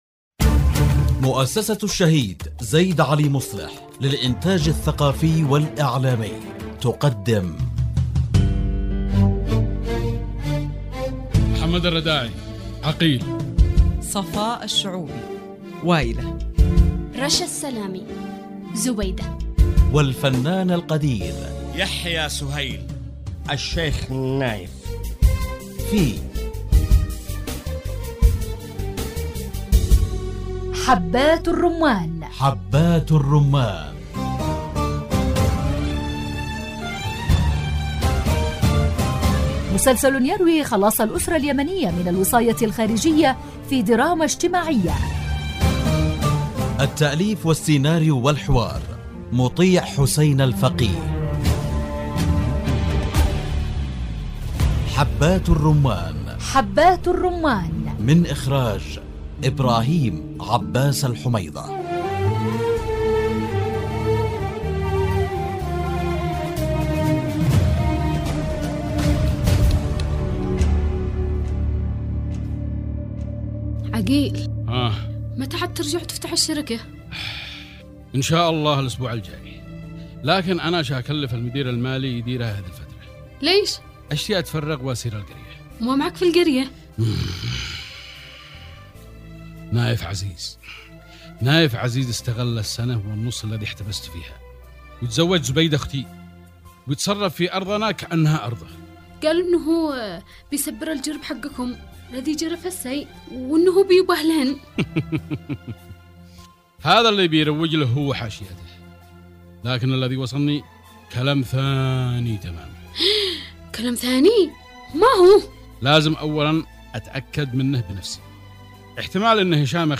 الدراما